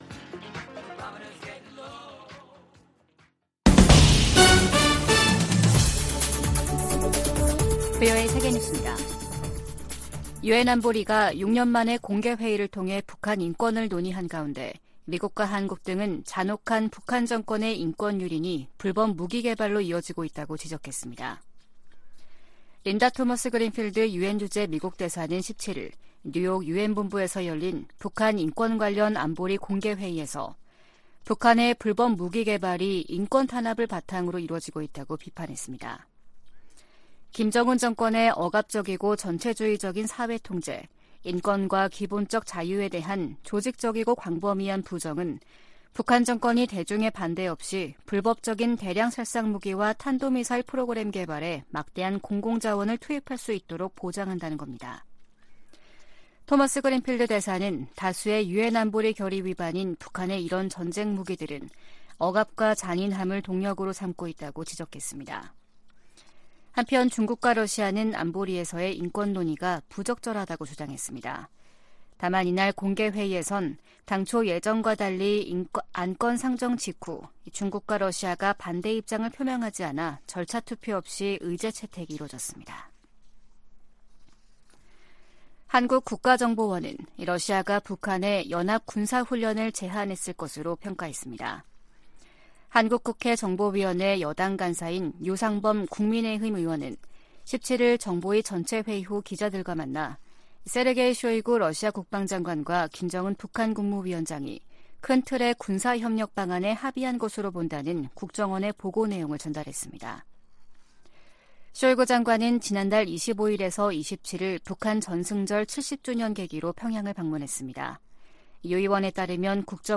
VOA 한국어 아침 뉴스 프로그램 '워싱턴 뉴스 광장' 2023년 8월 18일 방송입니다. 백악관은 미한일 정상회의에서 3국 협력을 강화하는 중요한 이니셔티브가 발표될 것이라고 밝혔습니다. 백악관은 자진 월북한 주한미군 병사가 망명을 원한다는 북한의 발표를 신뢰하지 않는다고 밝혔습니다. 미 재무부가 북한-러시아 간 불법 무기거래에 관해 러시아와 슬로바키아, 카자흐스탄 소재 기업 3곳을 제재했습니다.